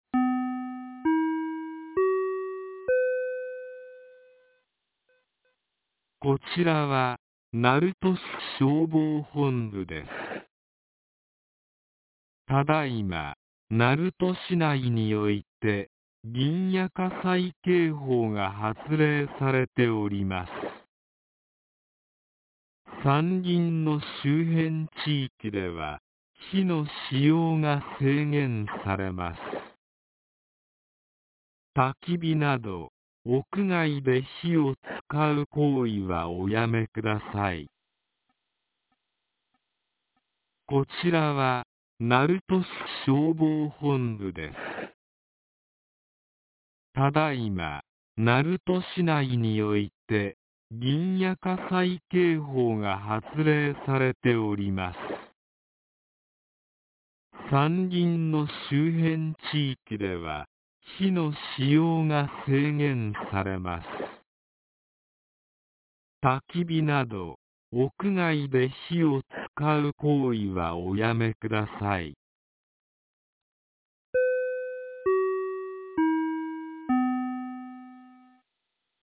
2026年01月23日 08時31分に、鳴門市より撫養町-南浜へ放送がありました。